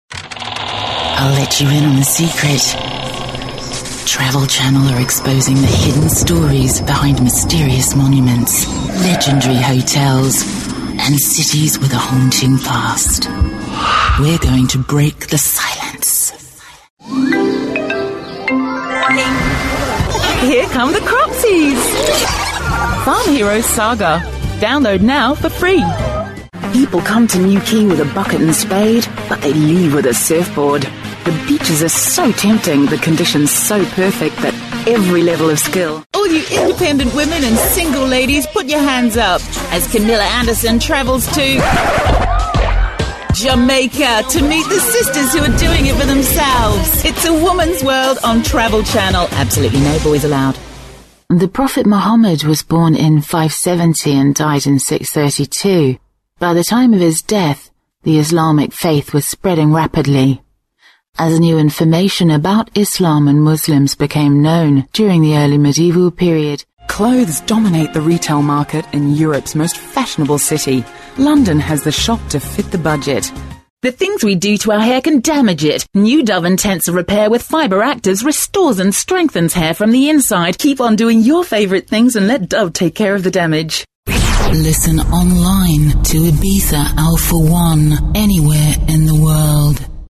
Female / 30s, 40s / English / New Zealand
Showreel